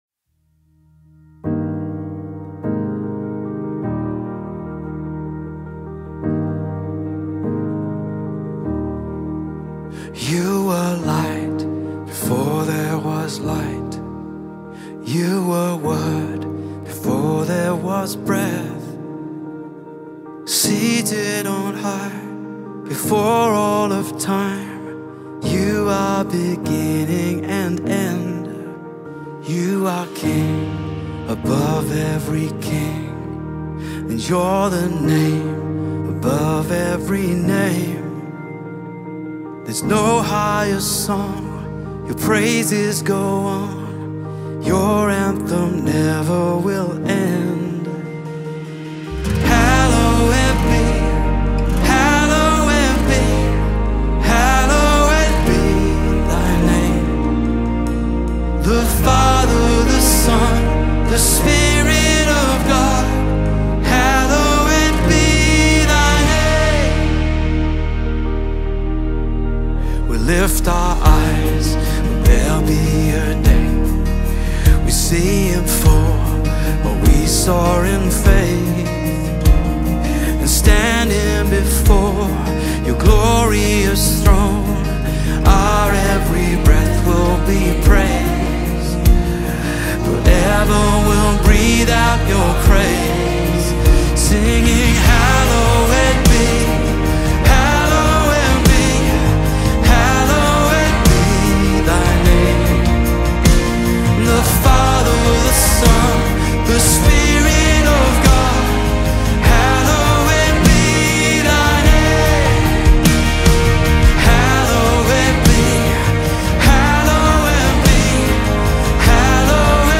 136 просмотров 26 прослушиваний 3 скачивания BPM: 75